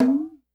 Perc_02.wav